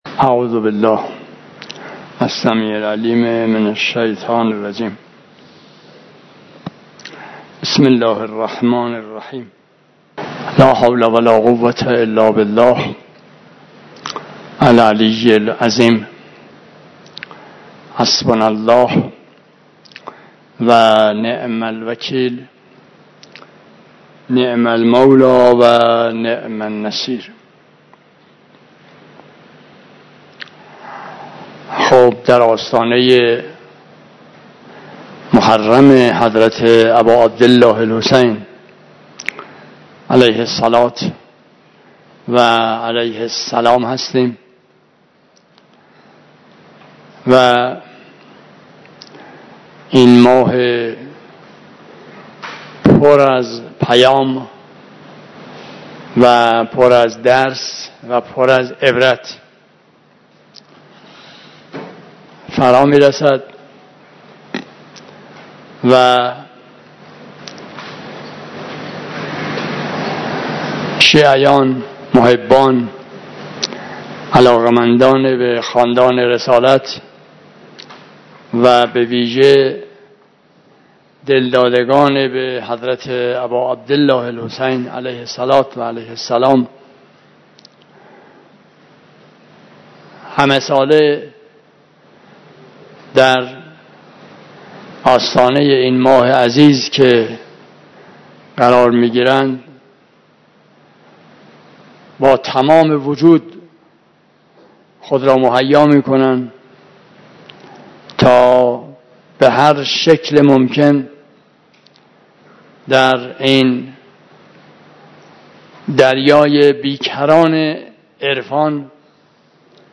نماینده ولی فقیه در چهارمحال‌وبختیاری امروز، ۲۹ مردادماه در جلسه معارف با مدیران استان با استناد به کتاب «حماسه حسینی» شهید مطهری، به بازخوانی درس‌هایی از مکتب أباعبدالله‌الحسین‌(ع) پرداخت.